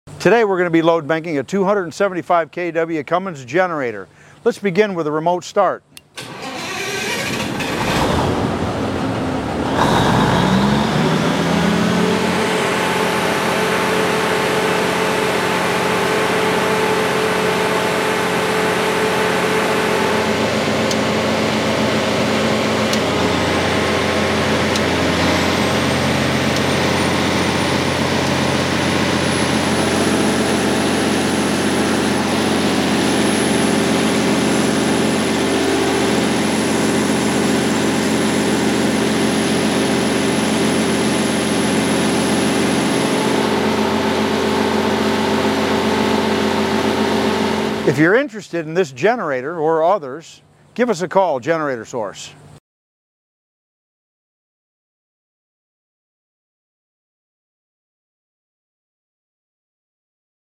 Load Bank Testing a 275kW sound effects free download
Load Bank Testing a 275kW sound effects free download By generator_source 0 Downloads 2 weeks ago 60 seconds generator_source Sound Effects About Load Bank Testing a 275kW Mp3 Sound Effect Load Bank Testing a 275kW Cummins Diesel Generator For Sale This Standby Three-Phase generator runs on Diesel and comes with a 500 gallon tank, has an output of 277/480v, and is currently located in Jacksonville, Florida.